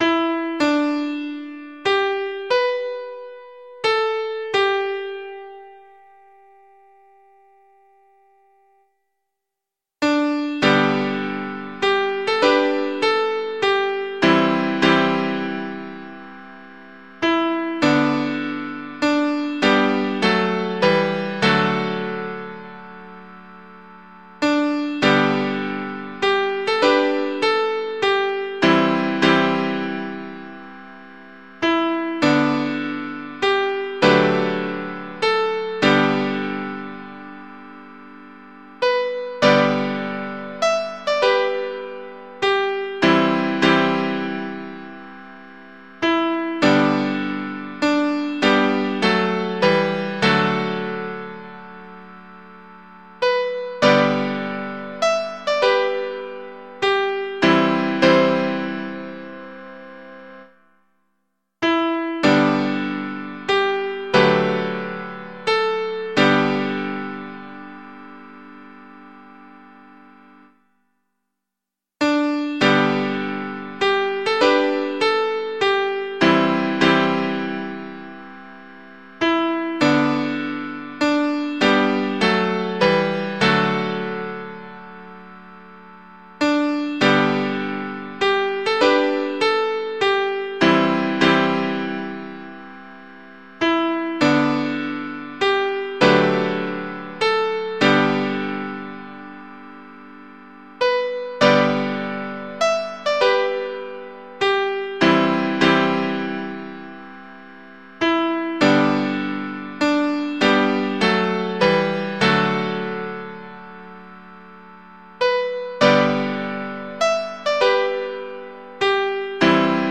Mp3 Audio of Tune Abc source